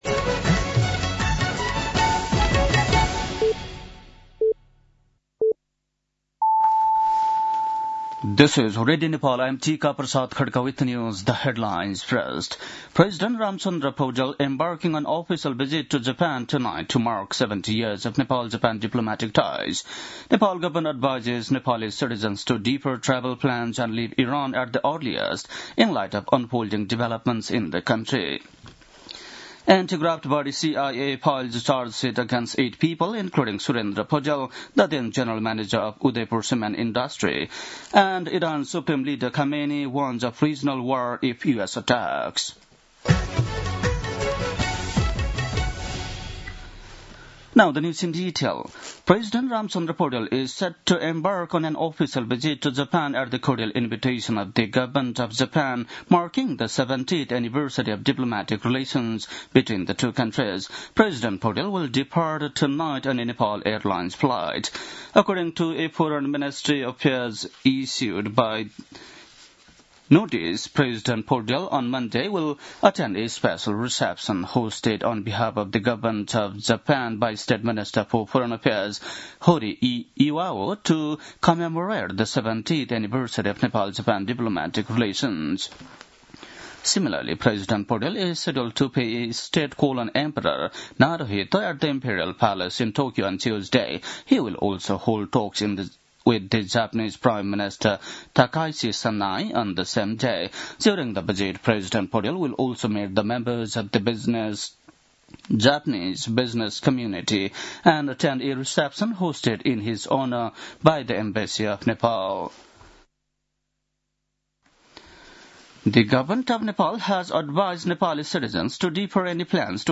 बेलुकी ८ बजेको अङ्ग्रेजी समाचार : १८ माघ , २०८२
8.-pm-english-news-.mp3